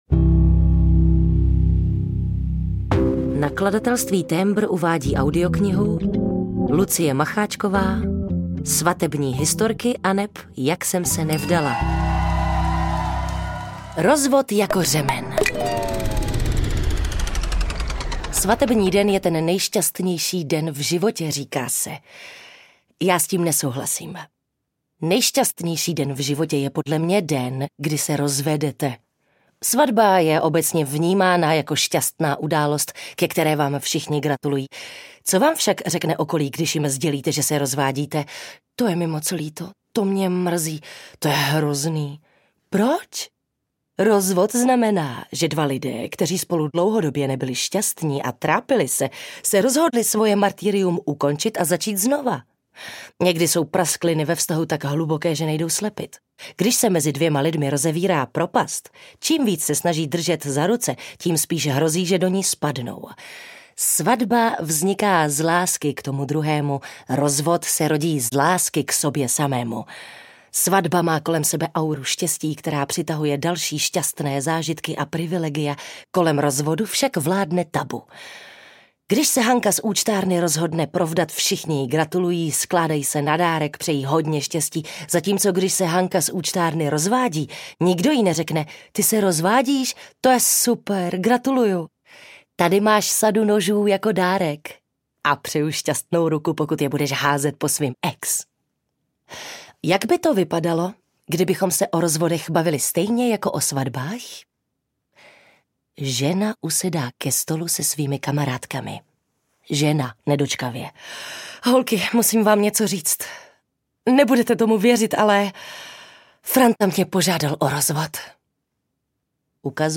Svatební historky aneb Jak jsem se nevdala audiokniha
Ukázka z knihy